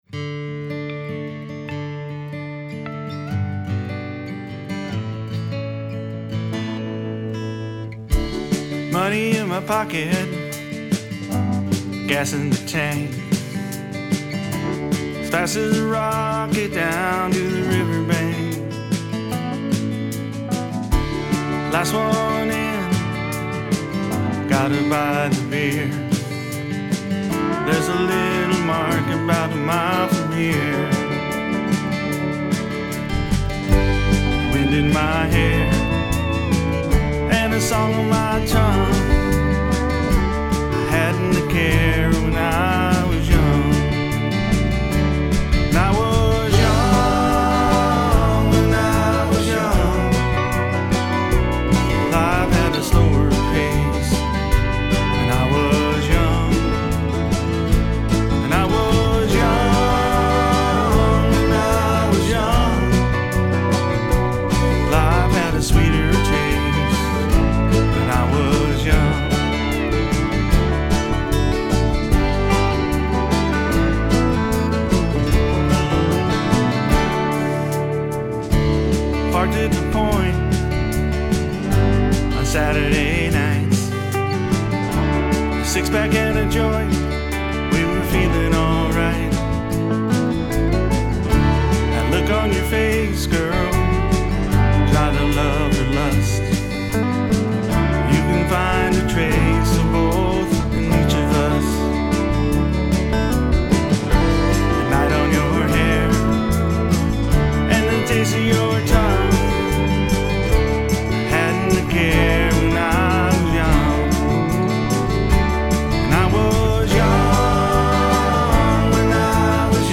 vocals, Acoustic guitar
MSA pedal steelel